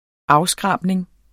Udtale [ ˈɑwˌsgʁɑˀbneŋ ]